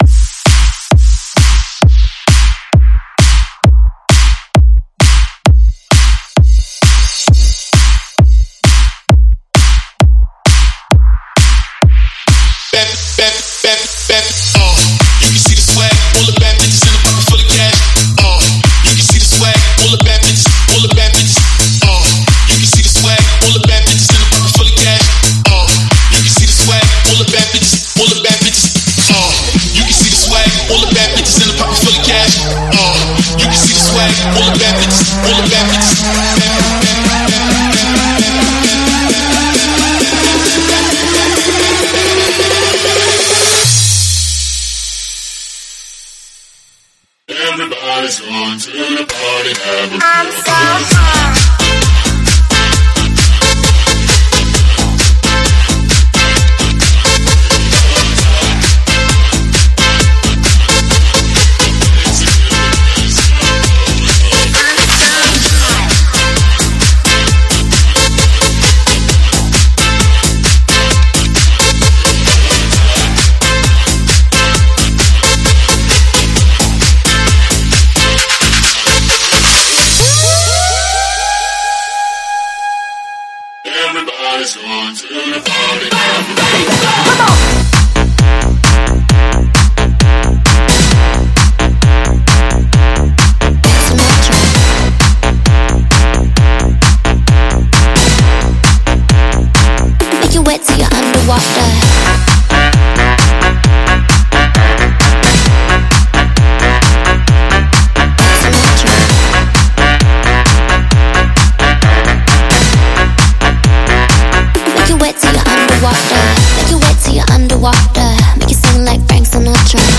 试听文件为低音质，下载后为无水印高音质文件 M币 8 超级会员 免费 购买下载 您当前未登录！